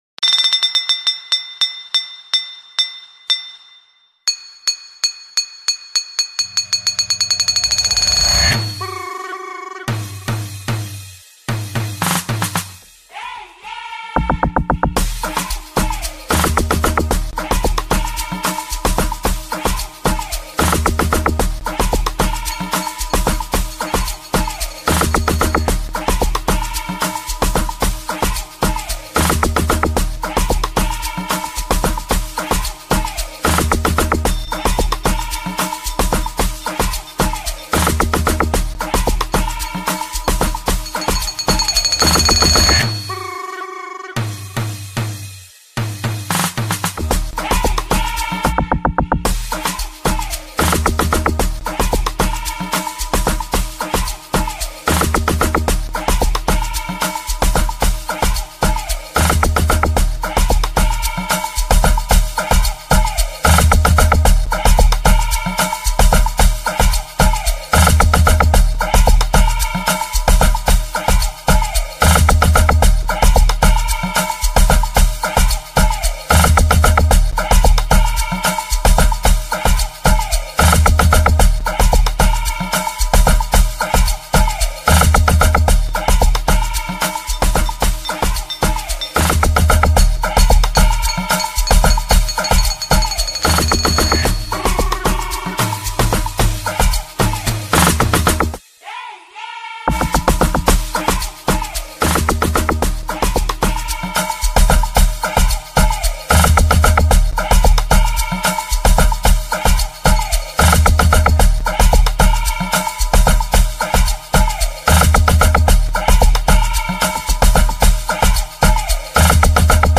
Amapiano
a record for the good and jolly times